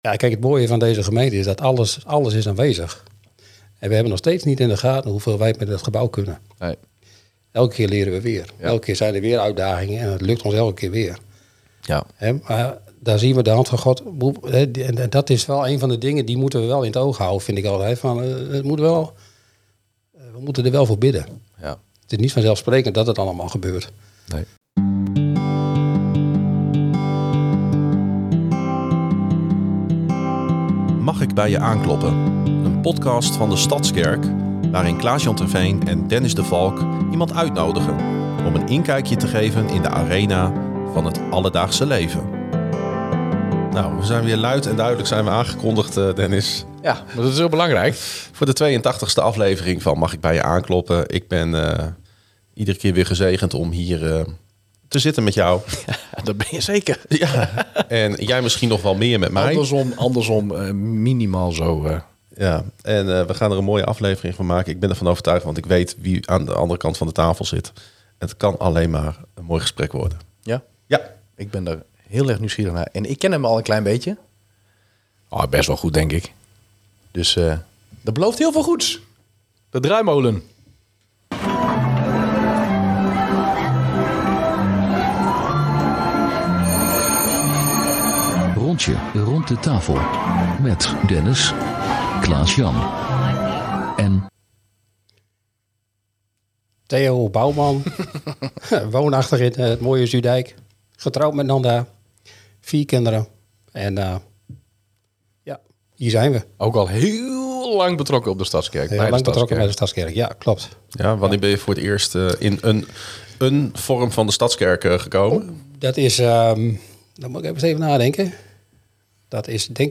De gastheren kloppen aan bij gemeenteleden en gasten om samen in gesprek te gaan.